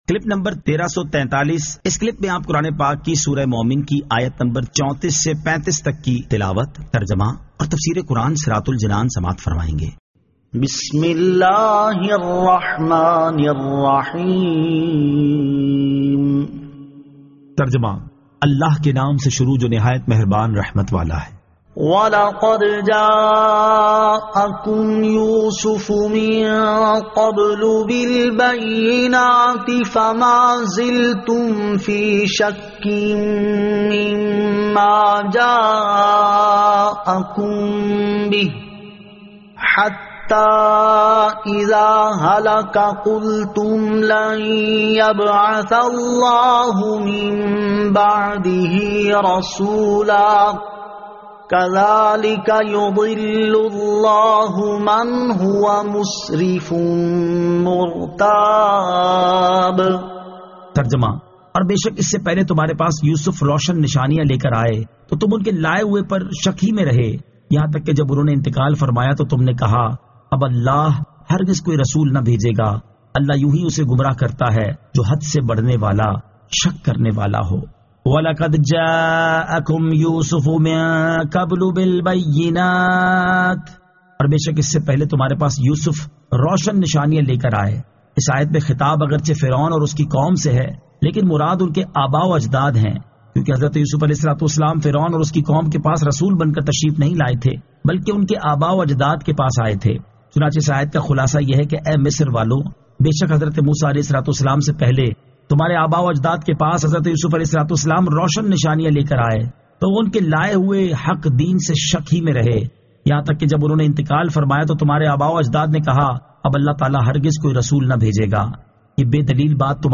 Surah Al-Mu'min 34 To 35 Tilawat , Tarjama , Tafseer